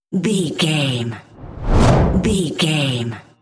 Whoosh deep fast
Sound Effects
Atonal
Fast
dark
intense
whoosh